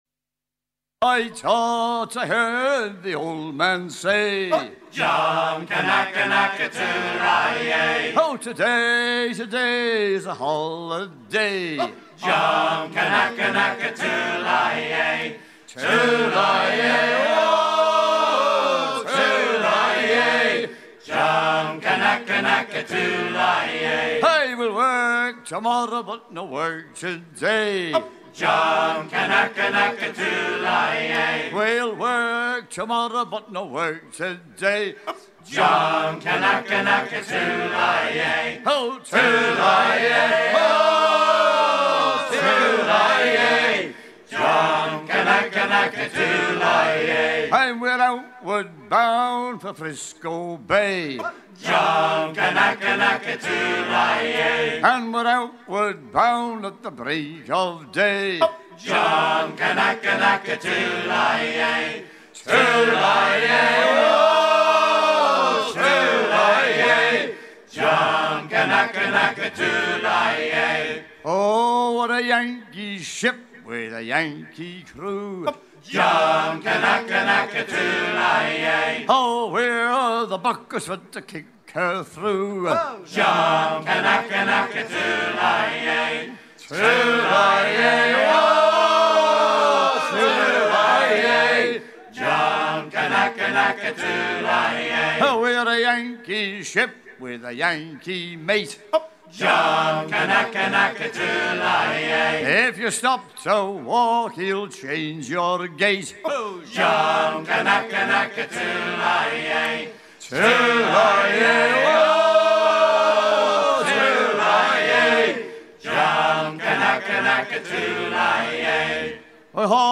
shanties polynésiens anglicisés
à hisser main sur main
Pièce musicale éditée